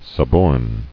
[sub·orn]